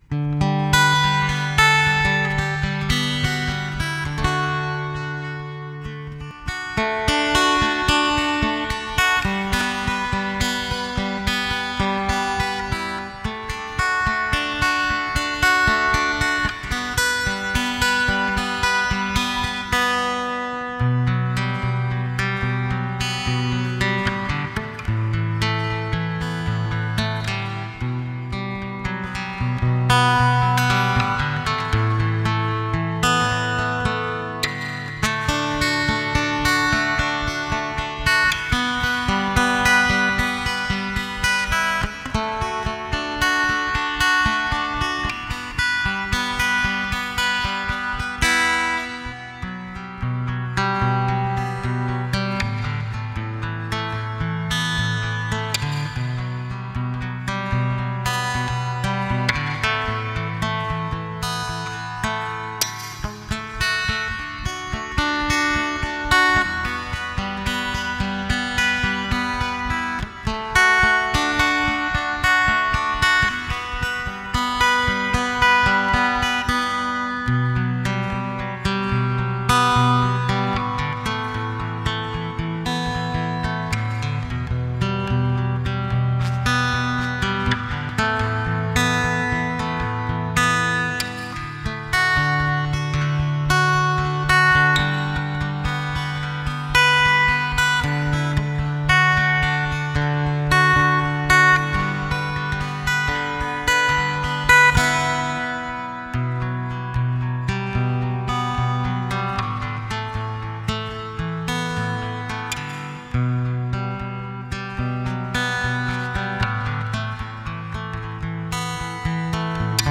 Musique Lo-FI